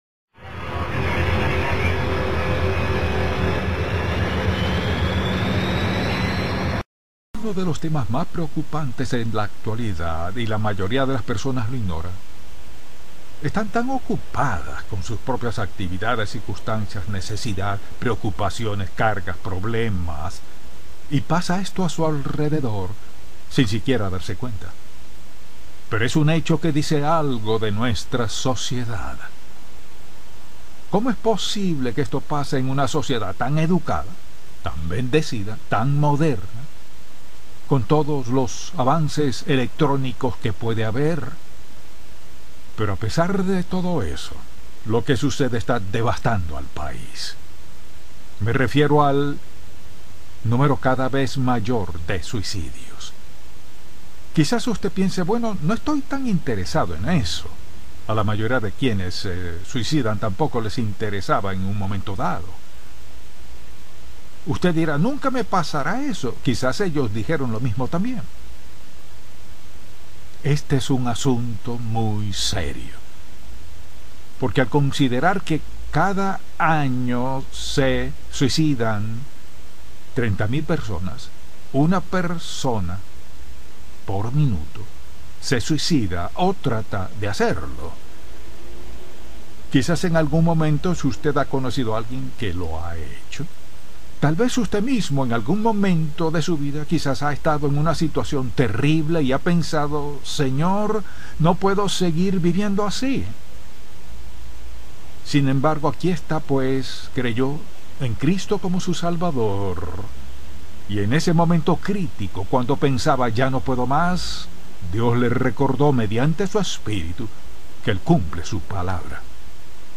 A continuación una excelente prédica: El suicidio y su impacto en los creyentes por Charles Stanley.